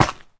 Minecraft Version Minecraft Version 25w18a Latest Release | Latest Snapshot 25w18a / assets / minecraft / sounds / block / froglight / step2.ogg Compare With Compare With Latest Release | Latest Snapshot
step2.ogg